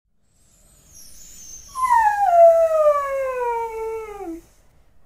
Dog Cry Sound Button - Free Download & Play
Dog Sounds2,228 views